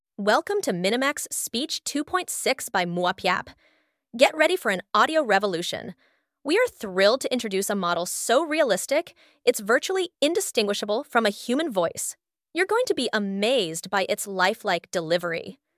Speech-2.6-turbo is Minimax’s fast, lightweight text-to-speech model designed for quick audio generation while maintaining good natural voice quality. It produces clear speech with smooth pacing and minimal delay.